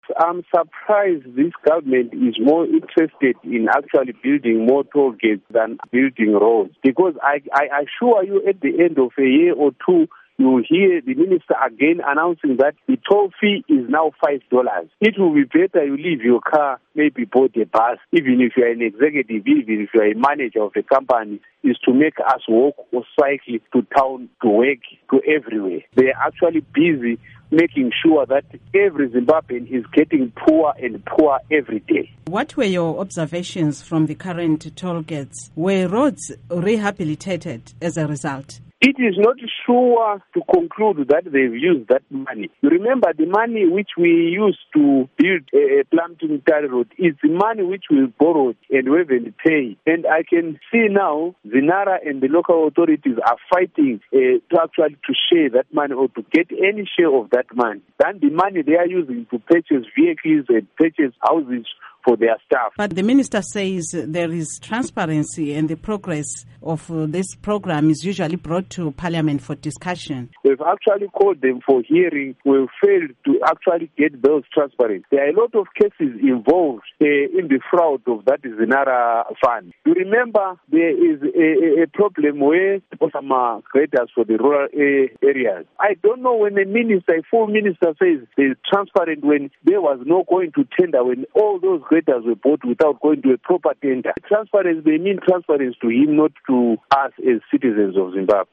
Interview with Albert Mhlanga